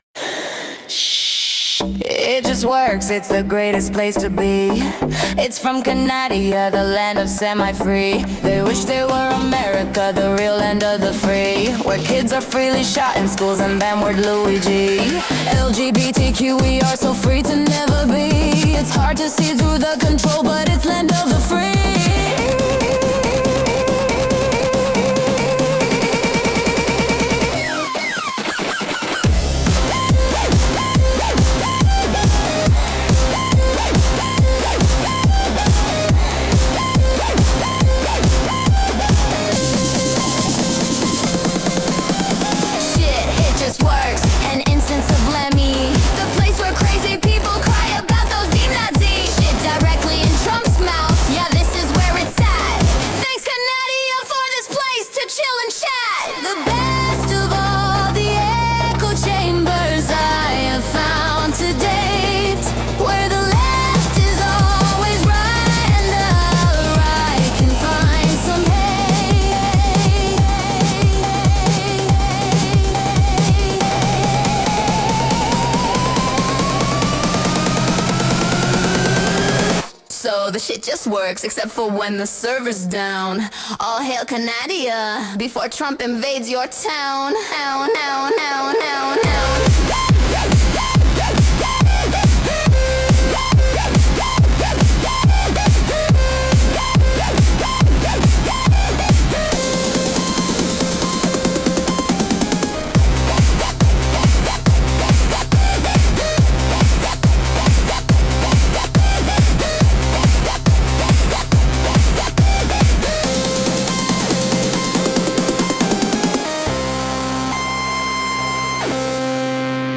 a thank you song